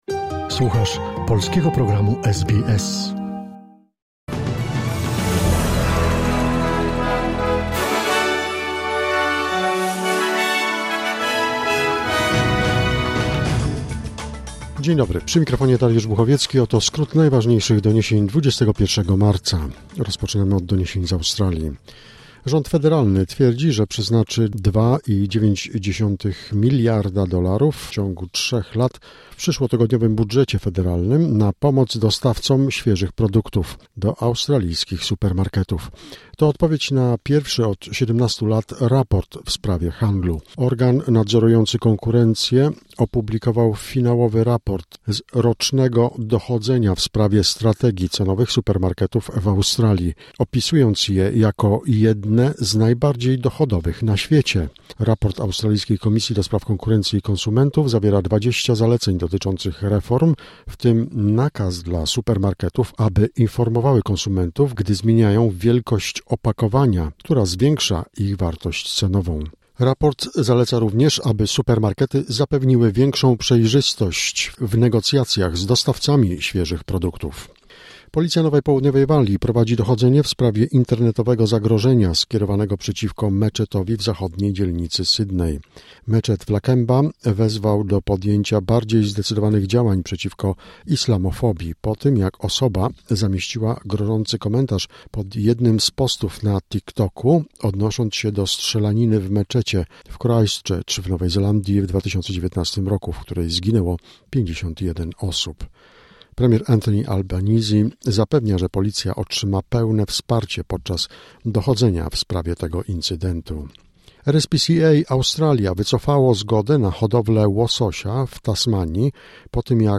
Wiadomości 21 marca SBS News Flash